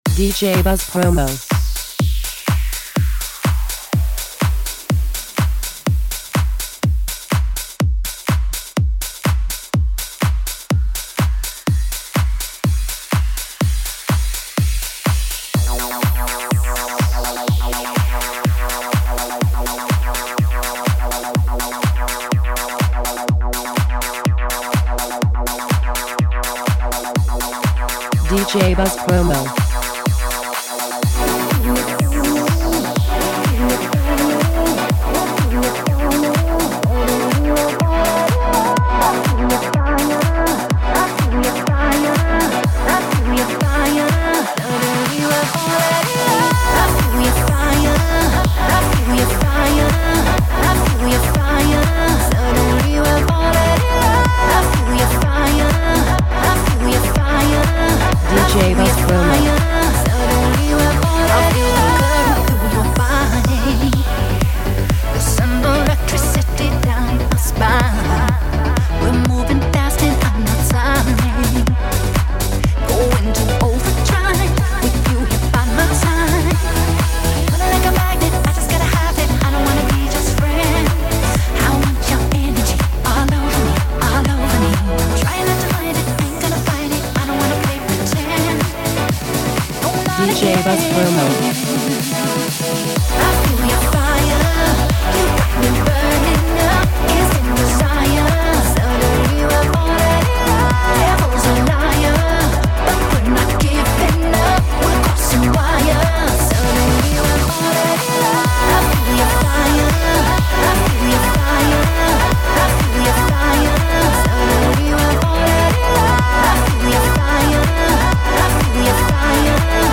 even more Nu Disco, with even more glitter and disco balls!